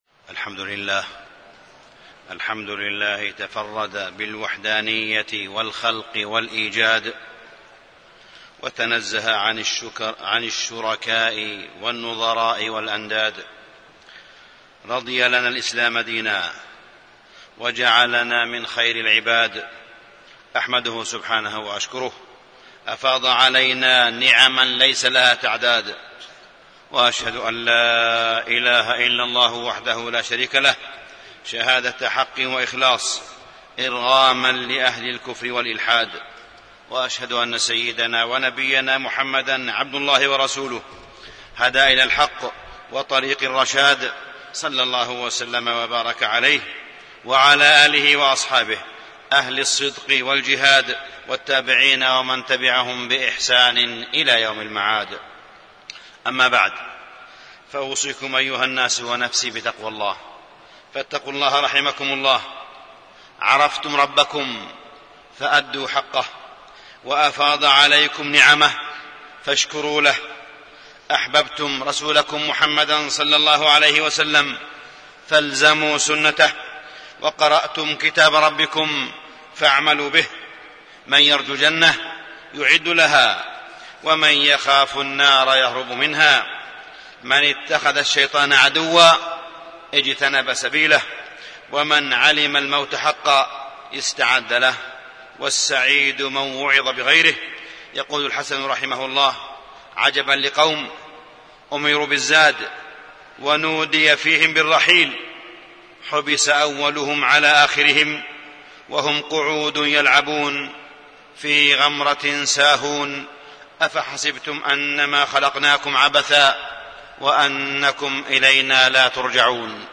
تاريخ النشر ٤ جمادى الأولى ١٤٣٢ هـ المكان: المسجد الحرام الشيخ: معالي الشيخ أ.د. صالح بن عبدالله بن حميد معالي الشيخ أ.د. صالح بن عبدالله بن حميد بلاد الحرمين ومميزاتها